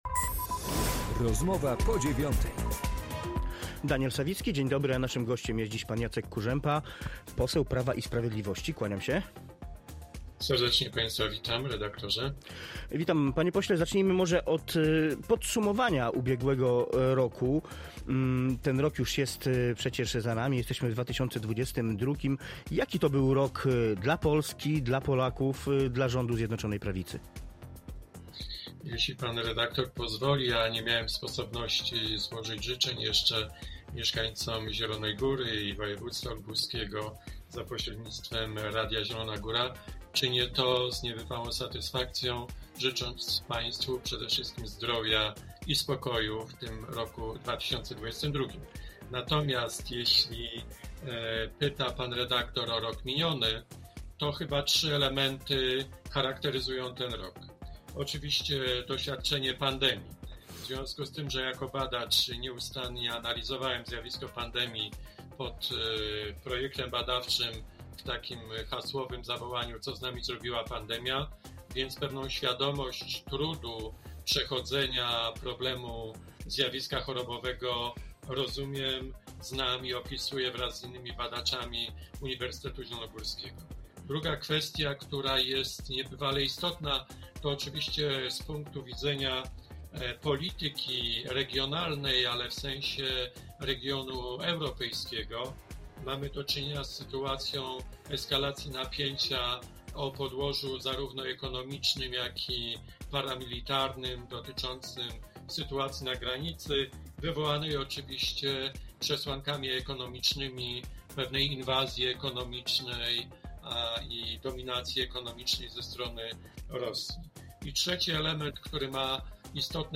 Rozmowa-po-9-Jacek-Kurzepa-posel-PiS.mp3